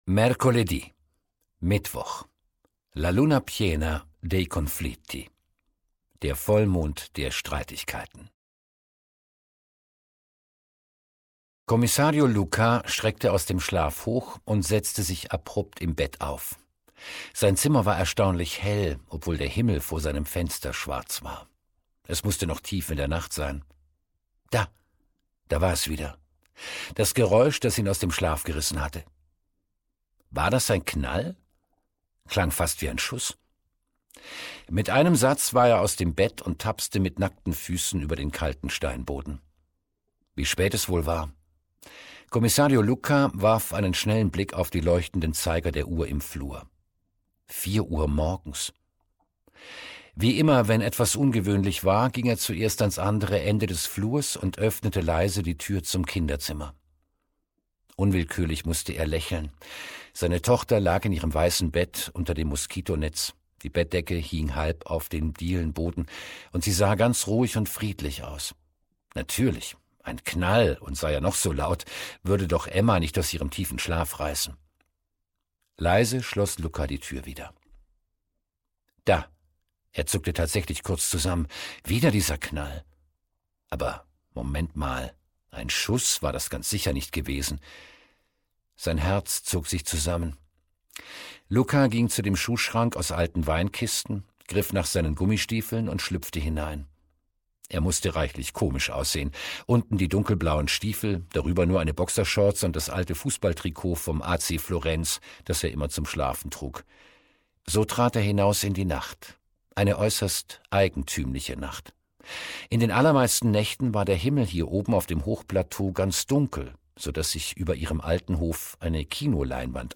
2023 | 1. Ungekürzte Ausgabe